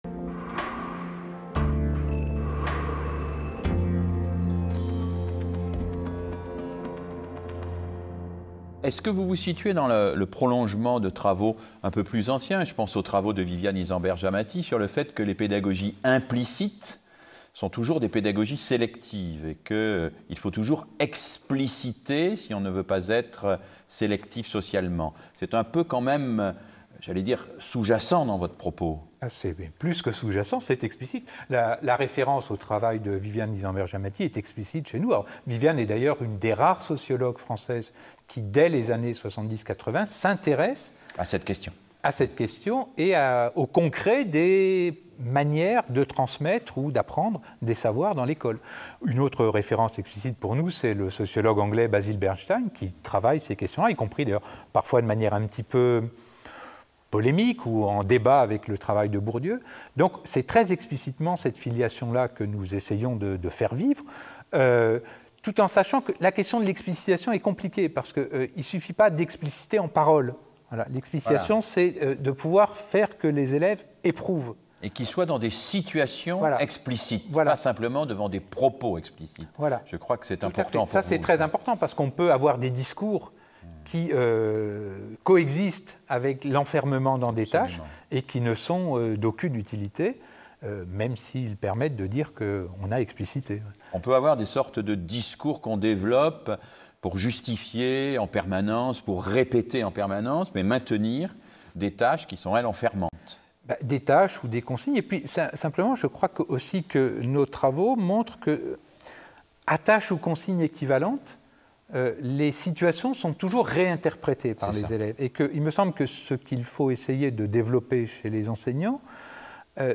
Extrait vidéo